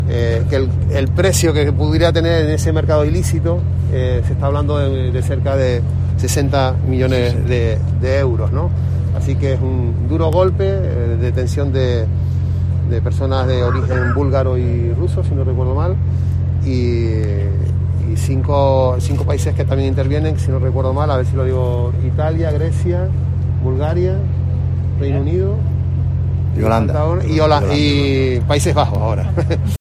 Anselmo Pestana, Delegado del Gobierno en Canarias